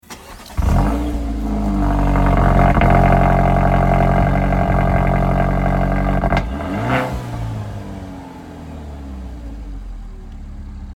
Écoutez le son du moteur !